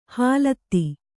♪ hālatti